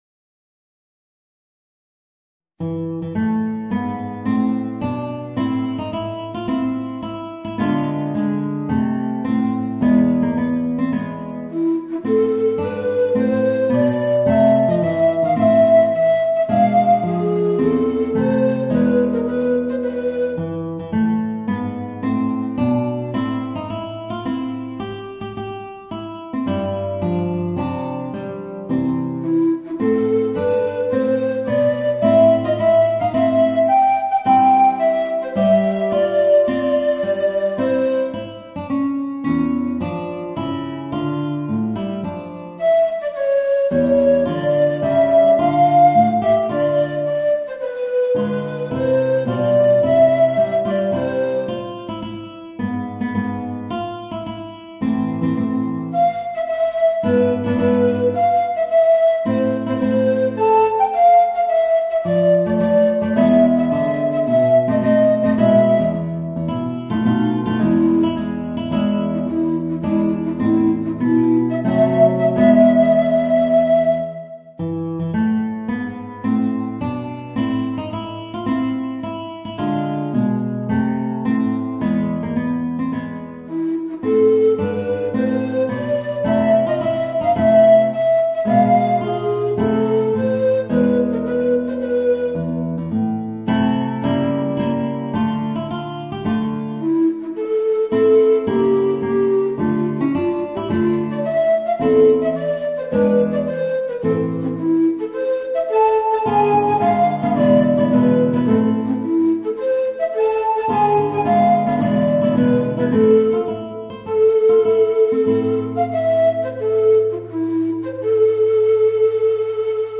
Voicing: Tenor Recorder and Guitar